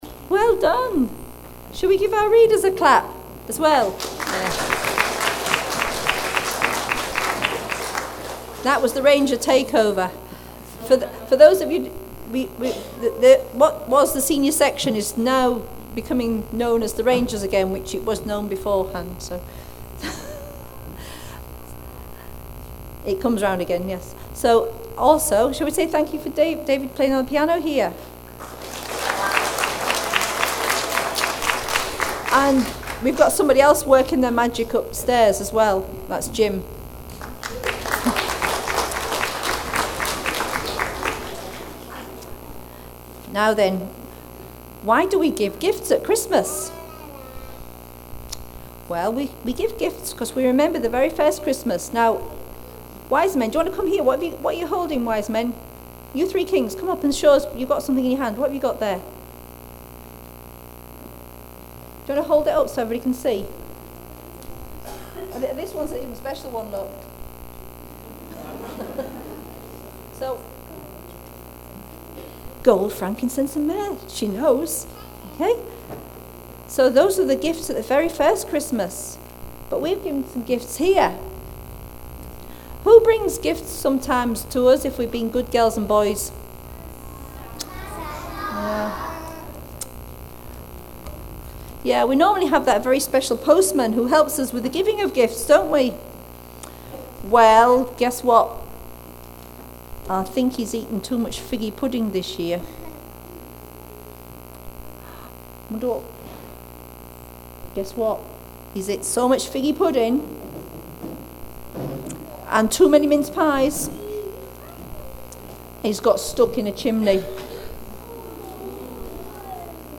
Click on the links to hear the carol singing and service
On Wednesday 5th of December St. Mungo's played host to Penicuik and Midlothian Girlguiding, Rangers, Brownies, Trefoil and Rainbows girls, parents and friends, for their annual Christmas Service.